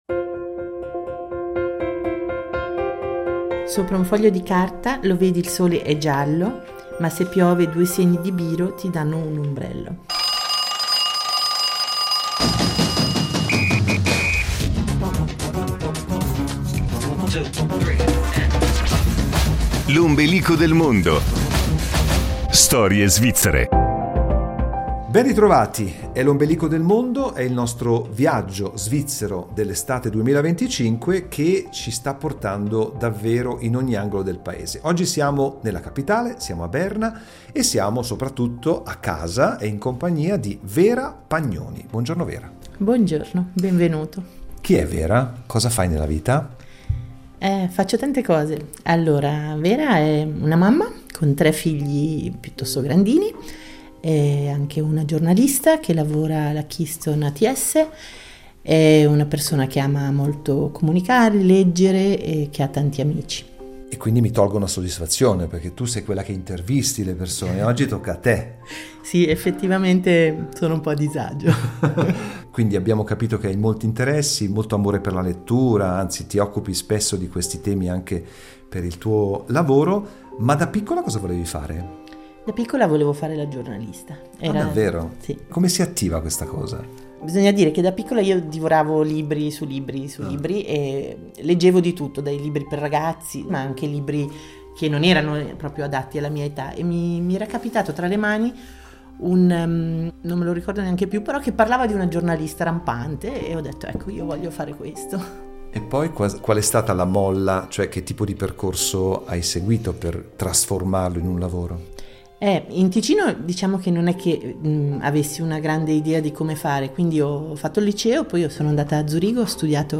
In questo episodio si racconta con grande serenità. E con parole altrettanto belle ci fa conoscere una nonna speciale: un fiore ribelle sbocciato molti anni fa.
Musicalmente ha scelto un brano di Toquinho che parla di vele, di mare, di pesci e di poesia.